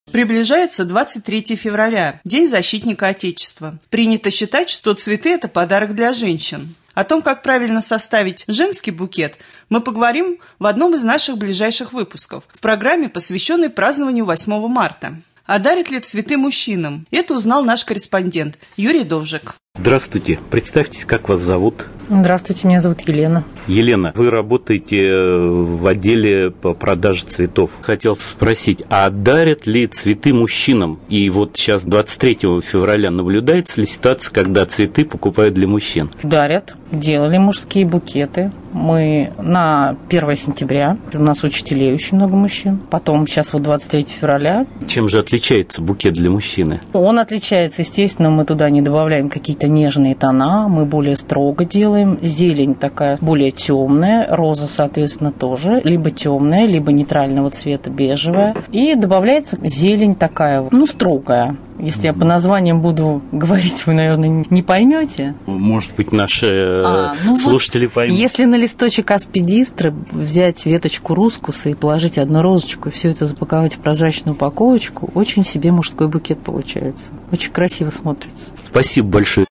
20.02.2015г. в эфире Раменского радио - РамМедиа - Раменский муниципальный округ - Раменское